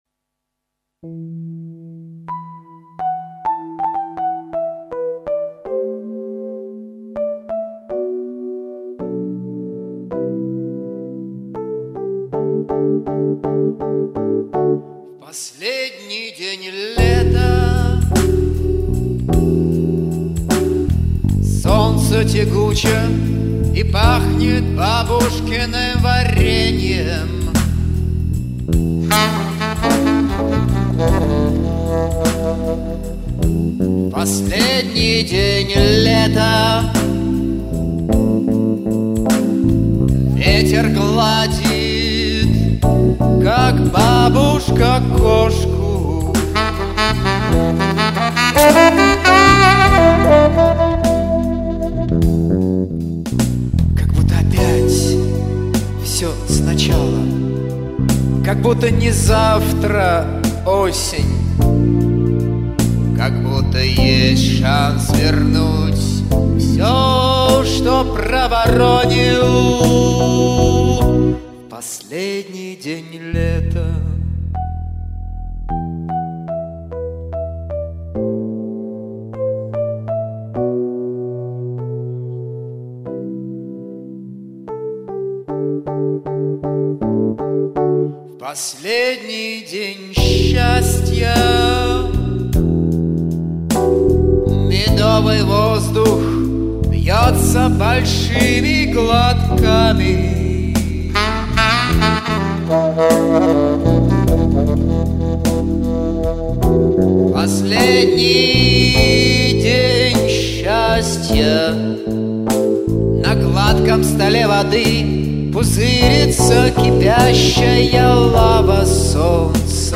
саксофон
• Жанр: Соул